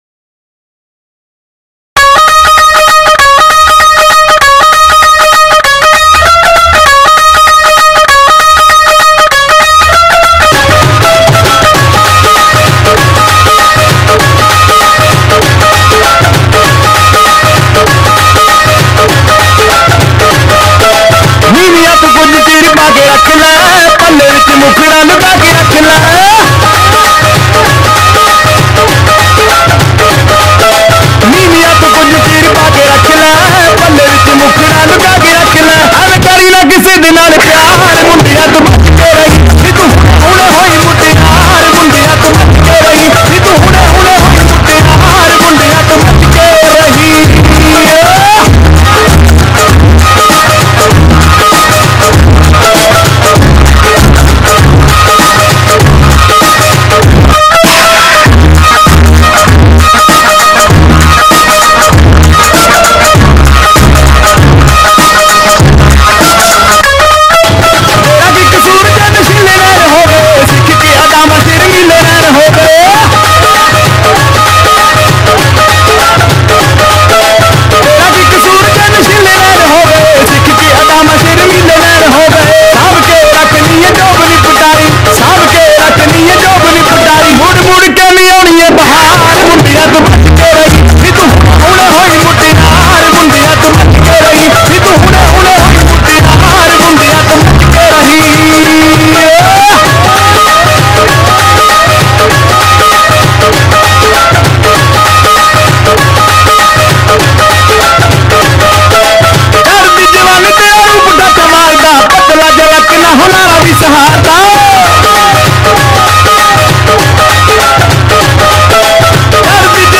extreme-loud-indian-music.mp3